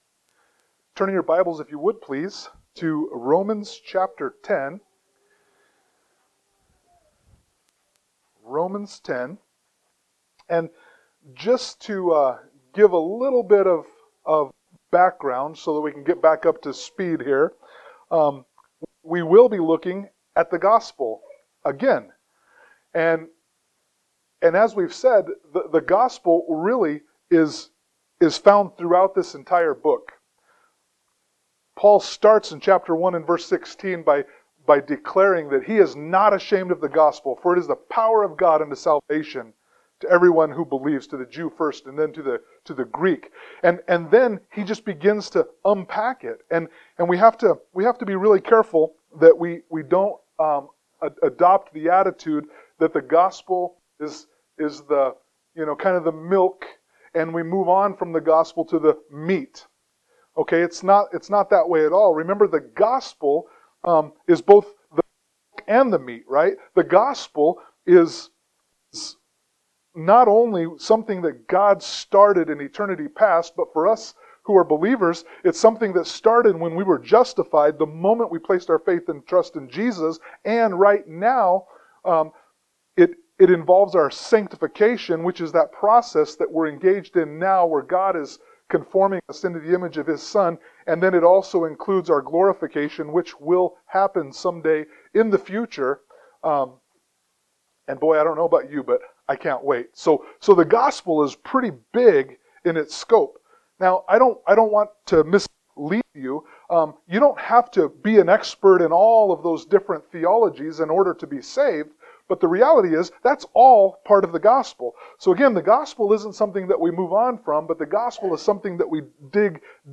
Romans 10:5-13 Service Type: Sunday Morning Worship « Romans 10:1-4